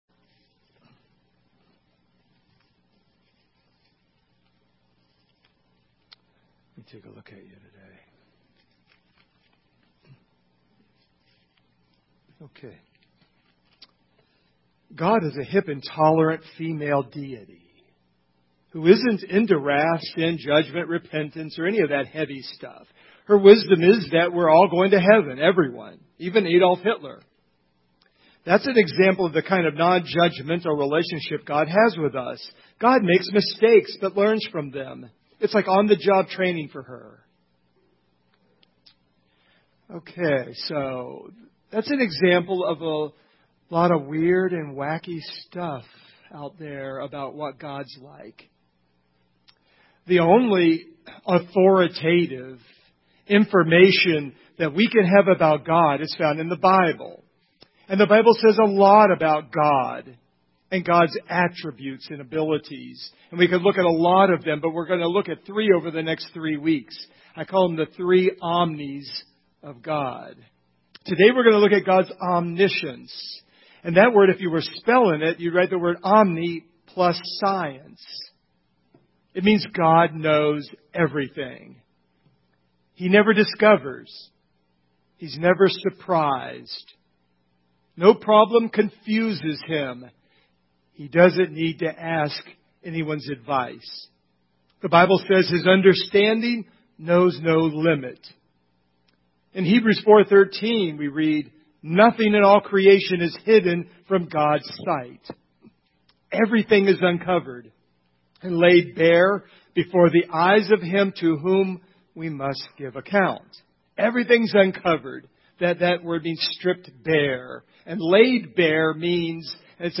Hebrews 4:13 Service Type: Sunday Morning God is all knowing.